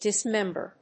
音節dis・mem・ber 発音記号・読み方
/dìsmémbɚ(米国英語), dìsmémbə(英国英語)/